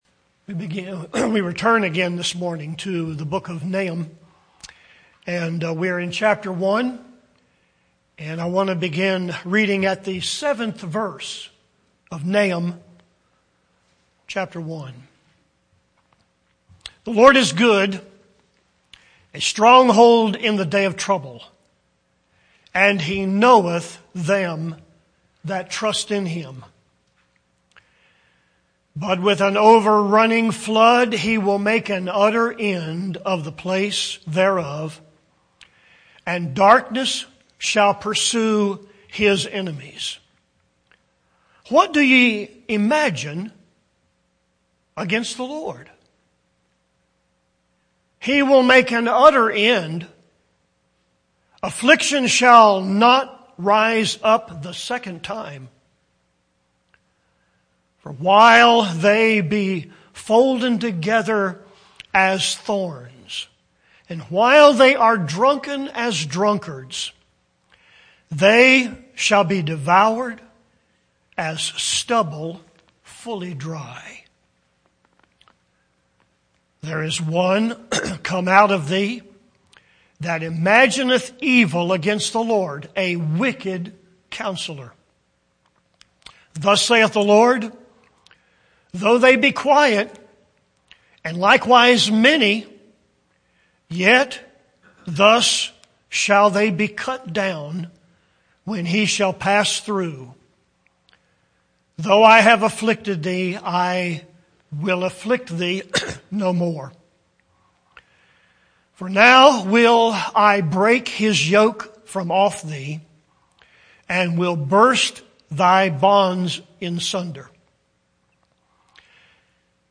Grace Fellowship Baptist Church, Arden, NC